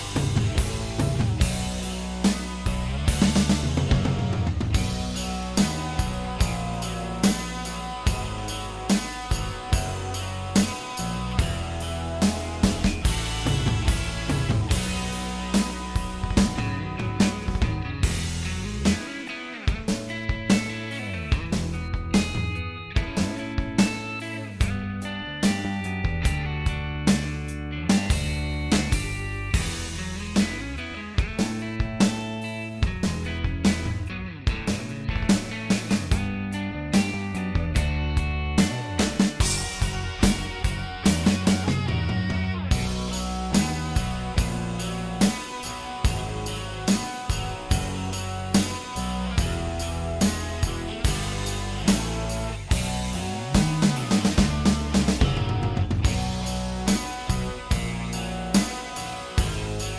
Minus All Guitars For Guitarists 5:21 Buy £1.50